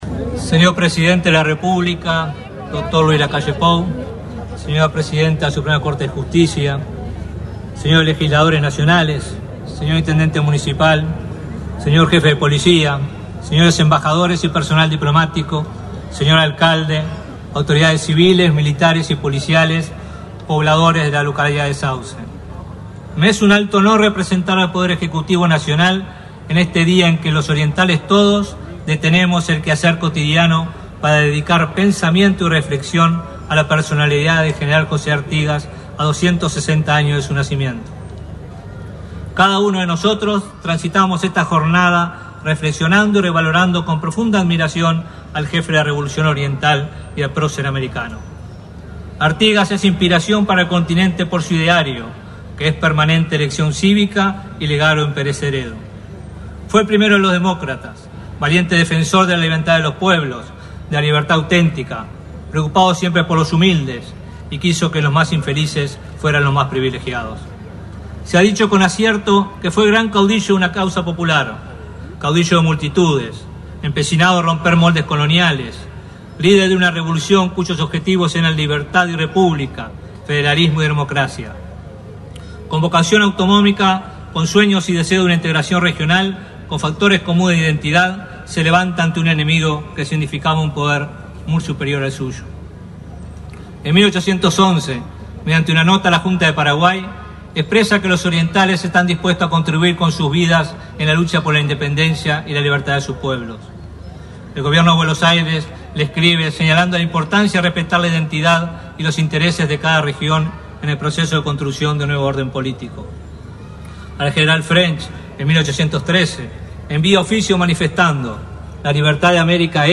Palabras del ministro de Ambiente, Robert Bouvier
Palabras del ministro de Ambiente, Robert Bouvier 19/06/2024 Compartir Facebook X Copiar enlace WhatsApp LinkedIn Con la presencia del presidente de la República, Luis Lacalle Pou, se realizó, este 19 de junio, un acto por el 260.° aniversario del natalicio de José Gervacio Artigas, en Sauce, departamento de Canelones. El ministro de Ambiente, Robert Bouvier, fue el orador en representación del Gobierno nacional.